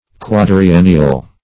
Quadriennial \Quad`ri*en"ni*al\, a.